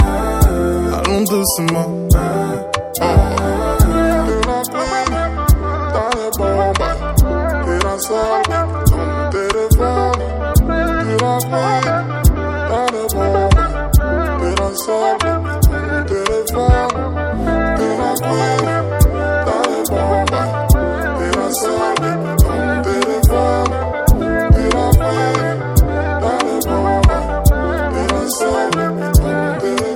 Плавные вокальные партии и чувственный бит
Глубокий соул-вокал и плотные хоровые партии
Pop R B Soul
Жанр: Поп музыка / R&B / Соул